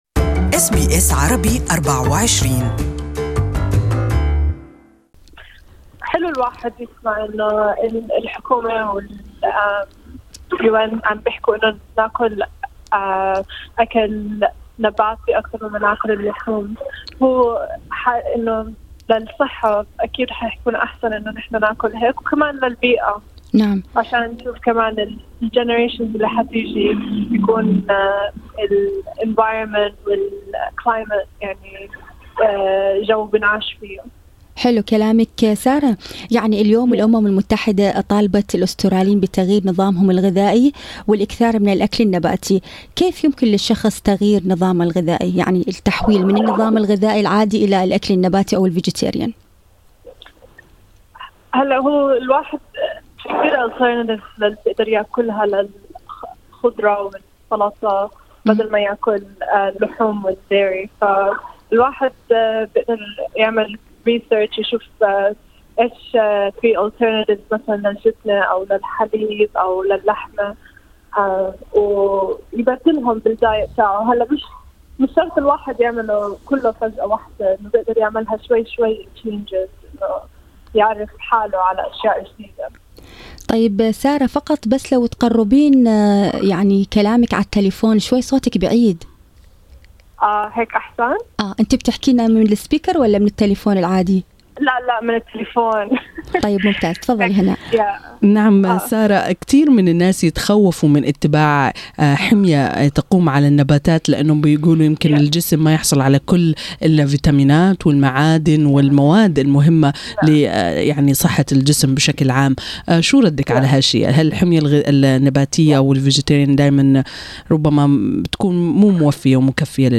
المزيد في لقاءِ مباشر مع المختصة بالأكل النباتي